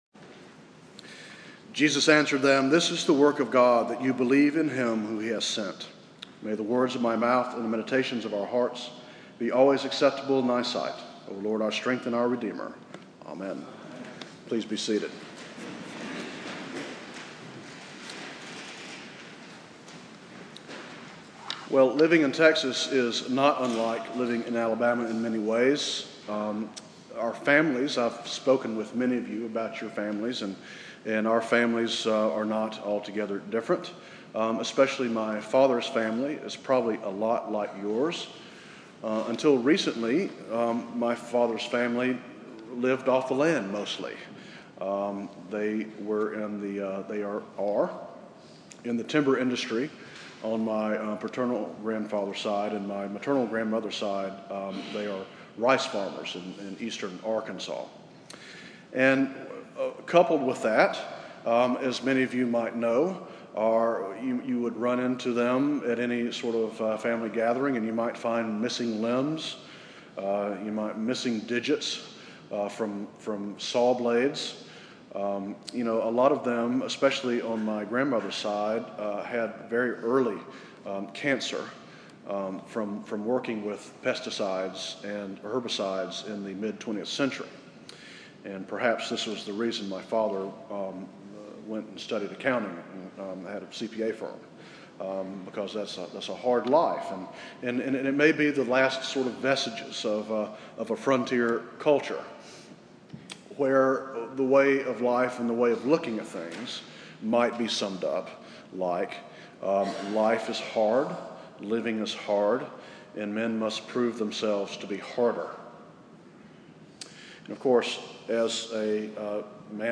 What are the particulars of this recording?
Venue: St. Thomas' Episcopal Houston Scripture: John 6:22-40